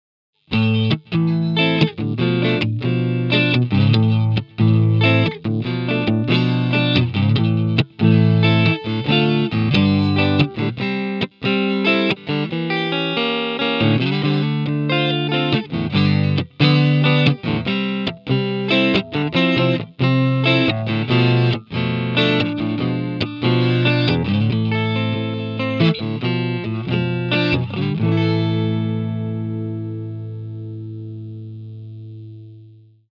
Gespielt habe ich je nach Sample eine Les Paul Style Gitarre, eine Strat oder eine Powerstrat. Als Box kam eine Marshall 4x12 mit Greenbacks zum Einsatz und abgenommen wurde mit einem SM57 direkt in den PC.
Clean
clean_08.mp3